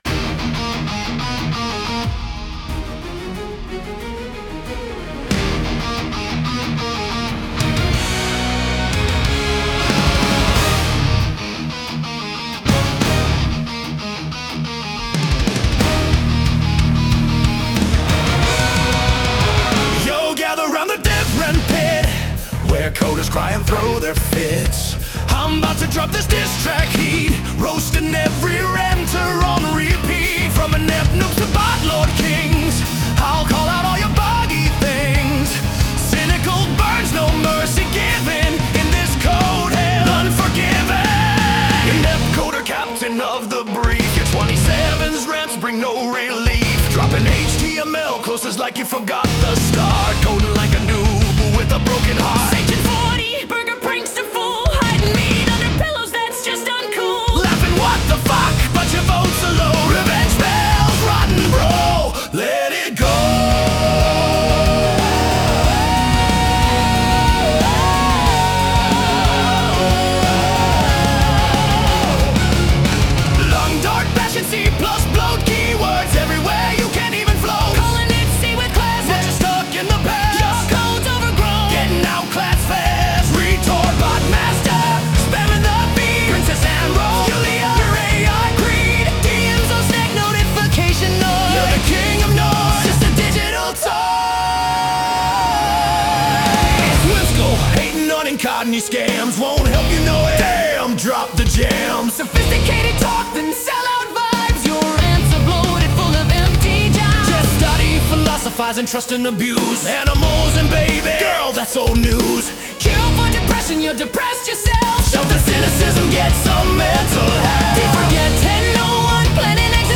A bit too fast maybe.